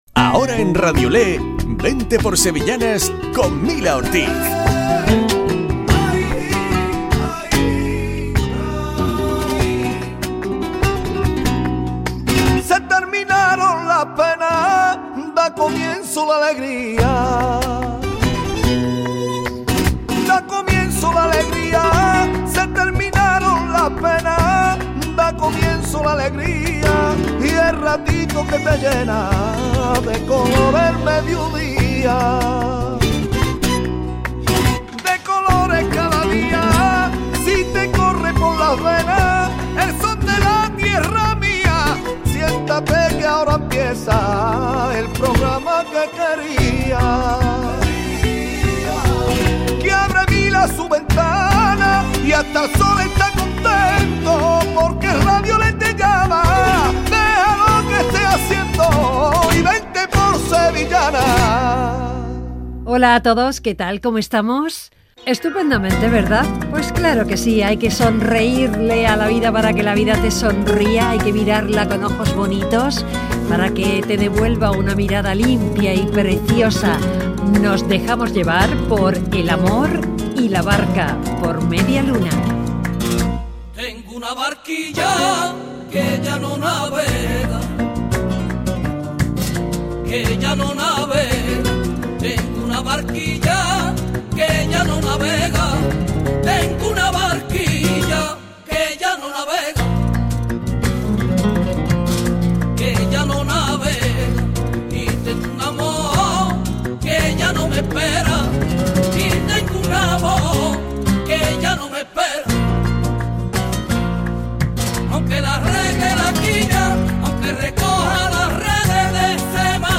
Programa dedicado a las sevillanas.